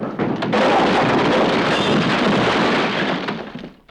Index of /90_sSampleCDs/E-MU Producer Series Vol. 3 – Hollywood Sound Effects/Human & Animal/Falling Branches
WOOD DEBR03R.wav